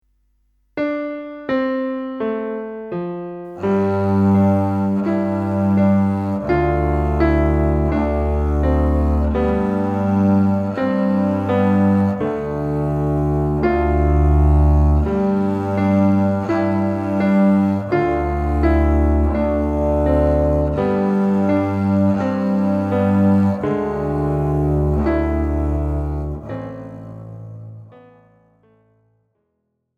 Gattung: für Kontrabass und Klavier